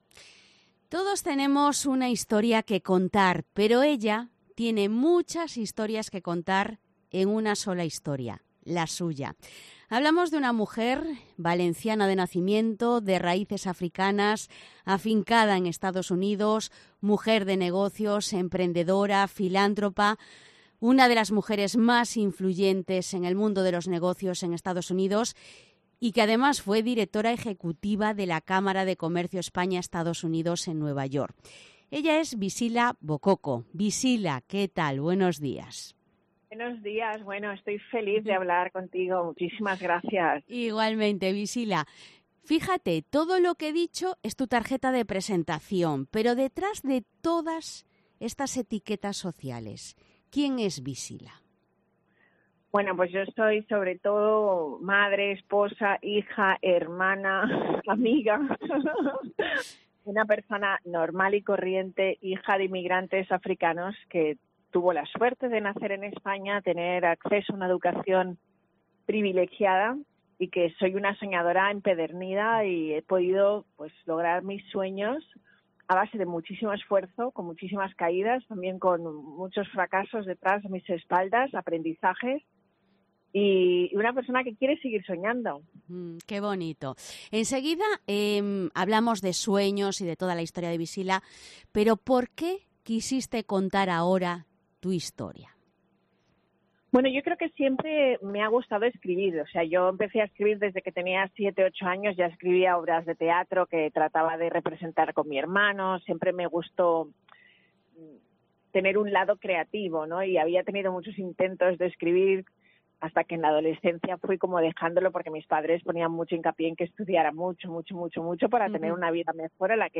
Entrevista a la emprendedora